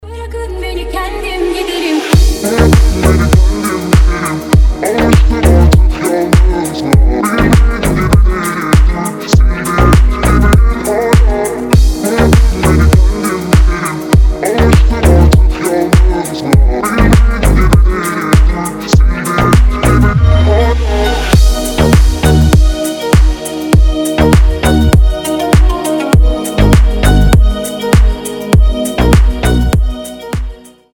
deep house
атмосферные
мелодичные
восточные
турецкие